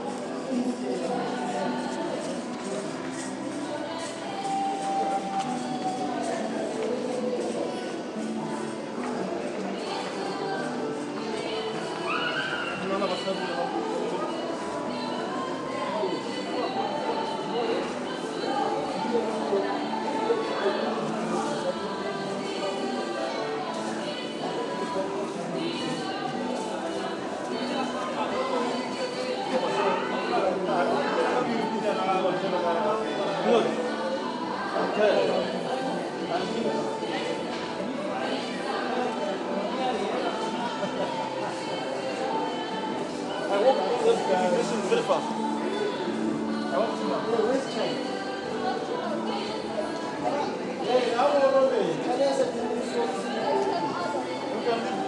Galo shopping Mall bussing with life